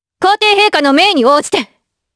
Chrisha-Vox_Skill6_jp.wav